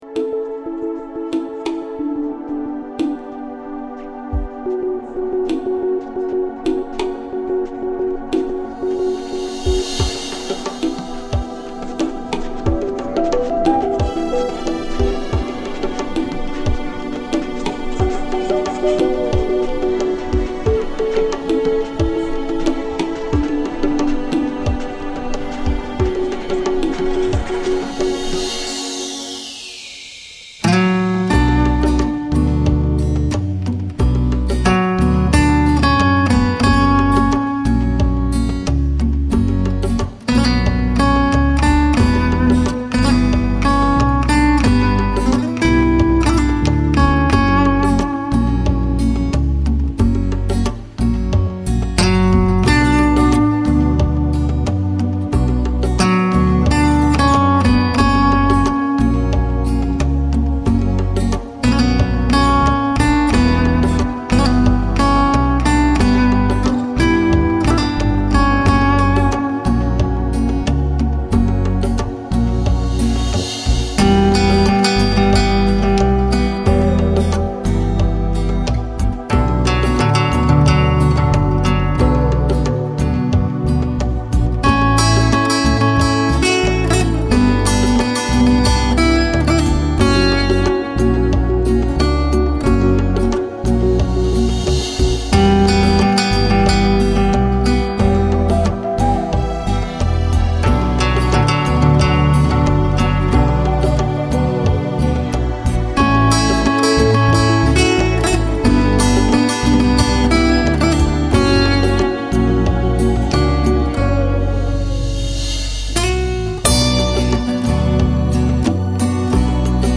румба...
rumba.mp3